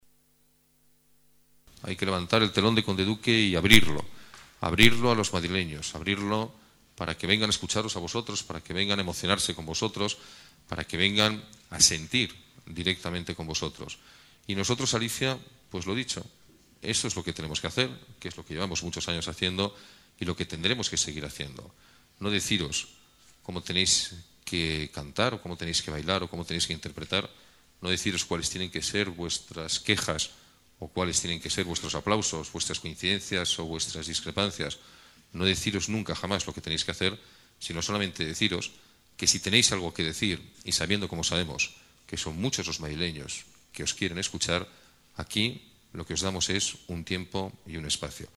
Nueva ventana:Declaraciones de Alberto Ruiz-Gallardón: Programación de Conde Duque